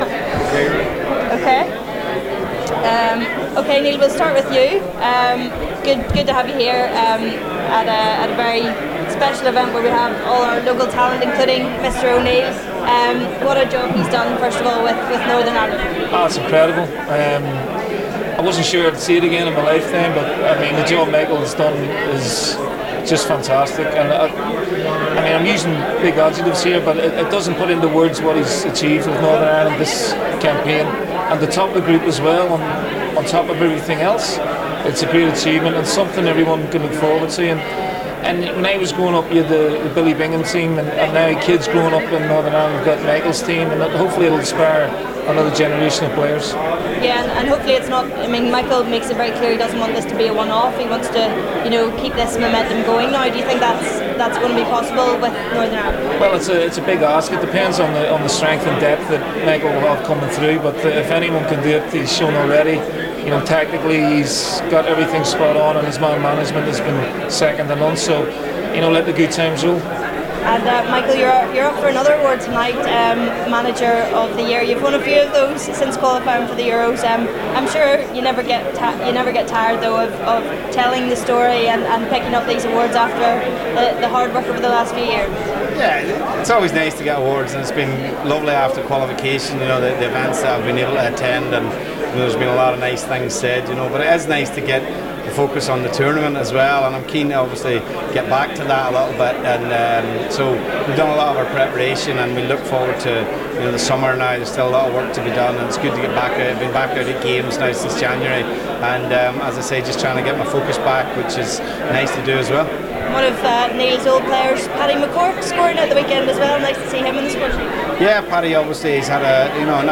We catch up with the respective bosses of Bolton Wanderers & Northern Ireland at the Belfast Telegraph Sports Awards.